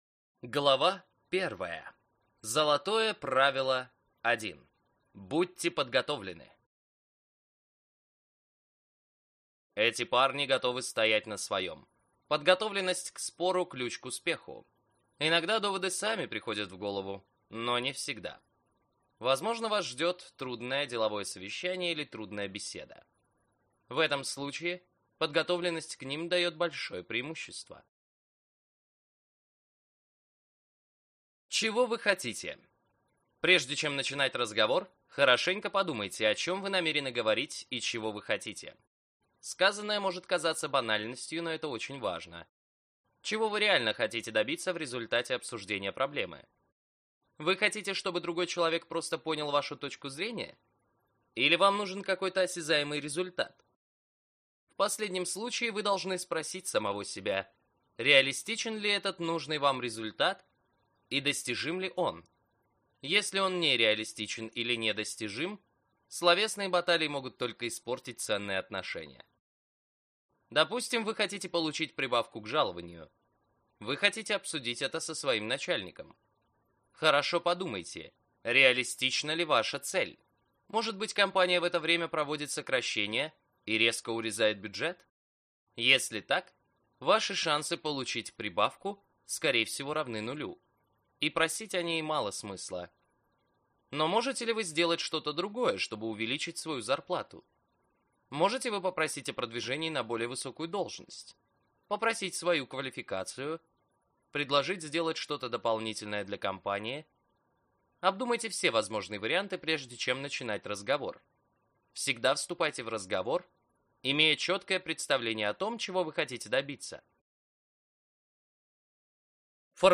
Аудиокнига Искусство полемики. Как дискутировать энергично, убедительно, уверенно | Библиотека аудиокниг